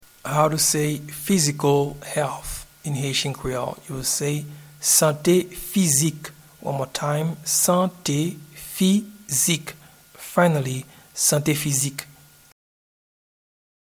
Pronunciation and Transcript:
Physical-Health-in-Haitian-Creole-Sante-Fizik.mp3